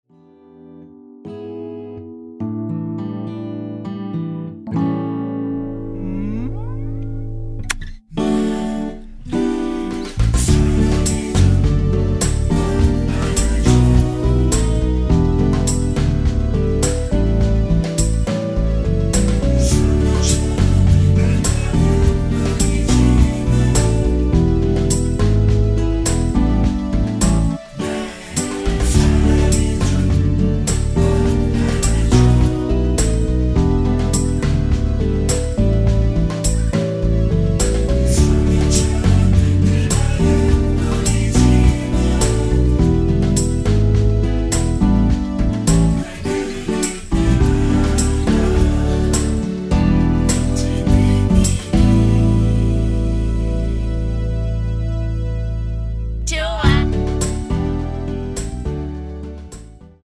(-2)코러스 포함된 MR입니다.(미리듣기 참조) 발매일 2000.09
◈ 곡명 옆 (-1)은 반음 내림, (+1)은 반음 올림 입니다.
앞부분30초, 뒷부분30초씩 편집해서 올려 드리고 있습니다.
중간에 음이 끈어지고 다시 나오는 이유는